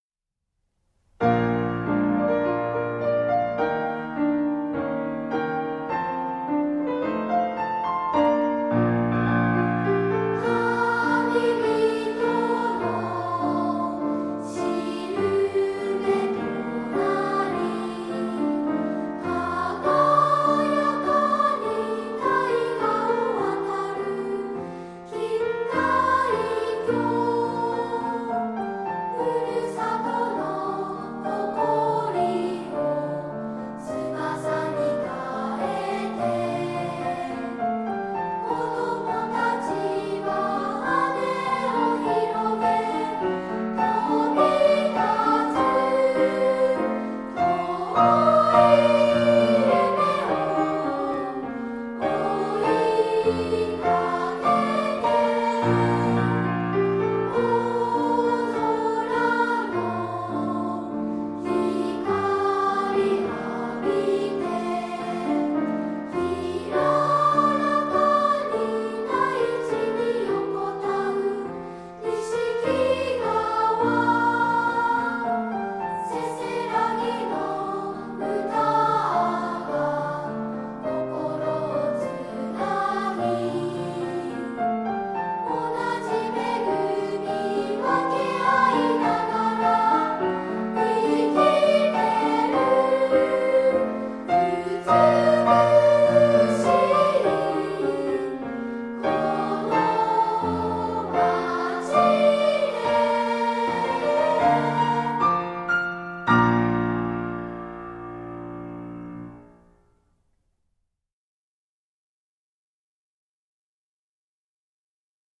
斉唱